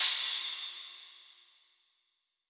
• Drum Crash C# Key 03.wav
Royality free crash cymbal audio clip tuned to the C# note.
drum-crash-c-sharp-key-03-2JO.wav